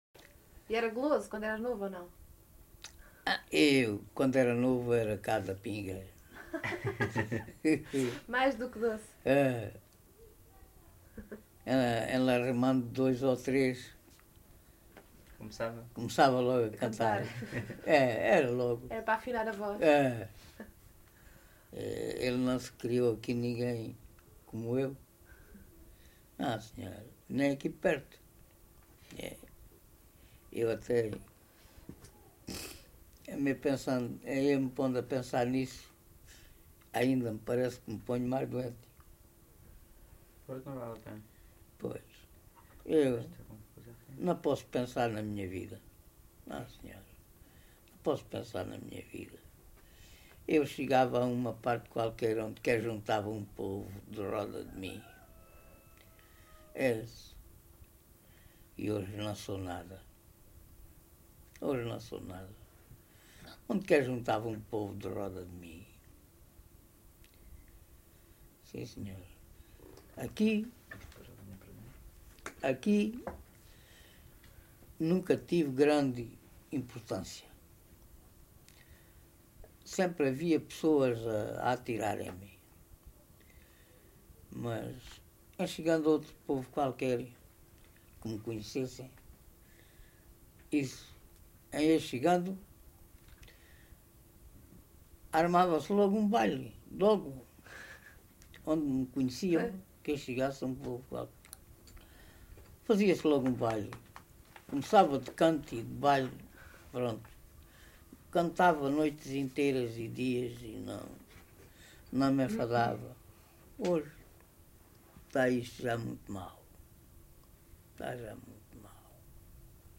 LocalidadeCarrapatelo (Reguengos de Monsaraz, Évora)